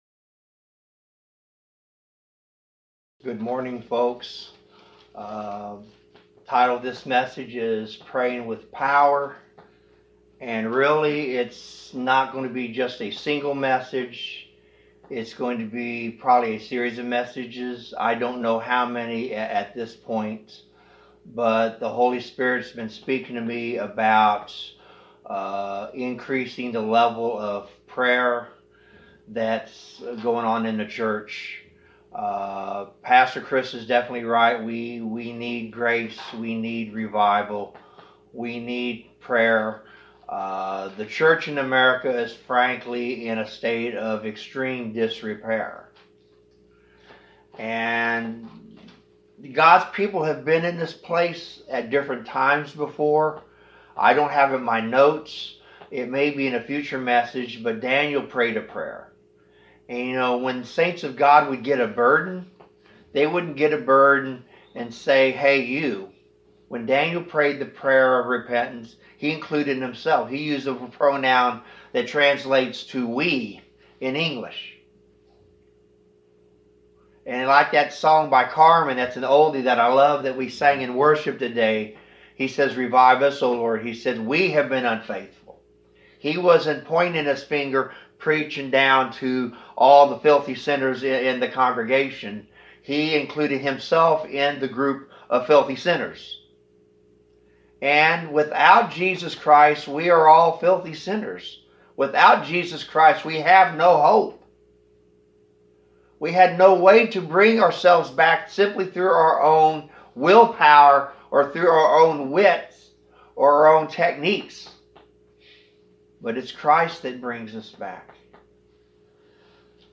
2 Chr 5-7 Service Type: Sunday Service Wonder why your prayers are not answered?